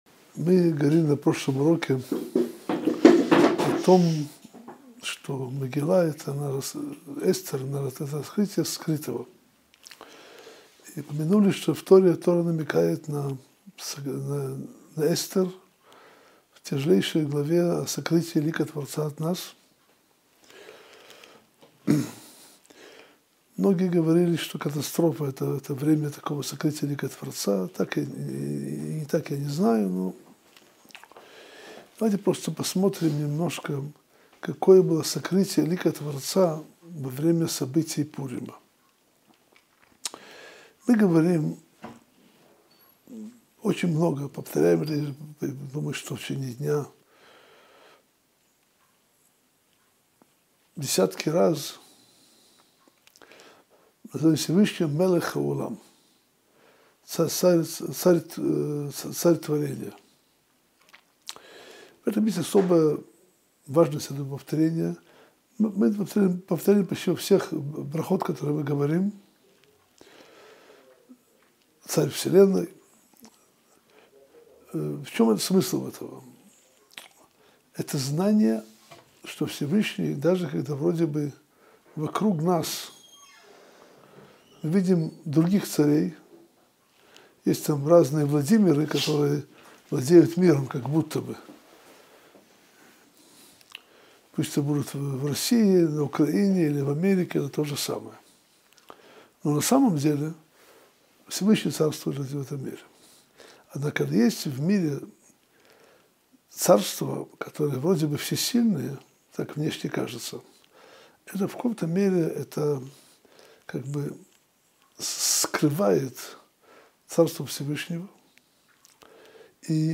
Уроки по Мегилат Эстер